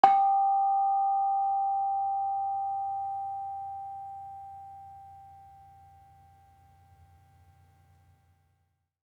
HSS-Gamelan-1 / Bonang
Bonang-G4-f.wav